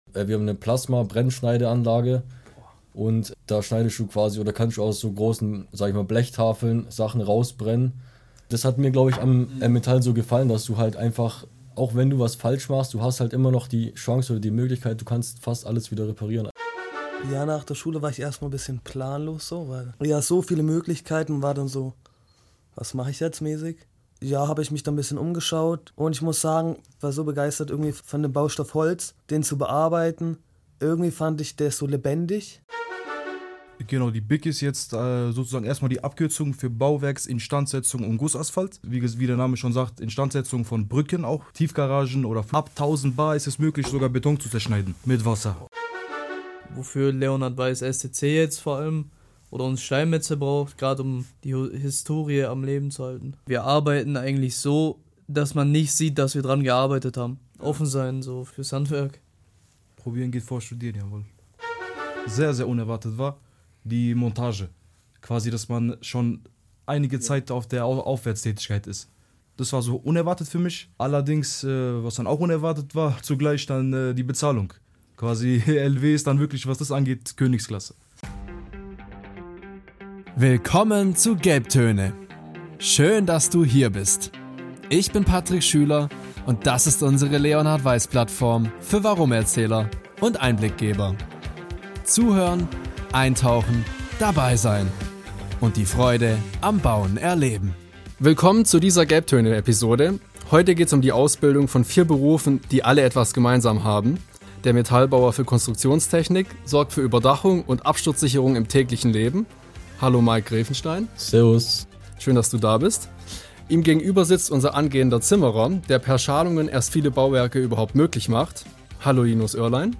Holz, Stahl, Beton oder Stein — welcher Baustoff passt zu dir? In dieser Folge sprechen vier Azubis über ihre Berufe, ihren Arbeitsalltag und die Faszination ihres Handwerks. Ehrlich, direkt und ohne Schnickschnack.